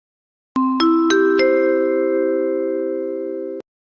call_tone.mp3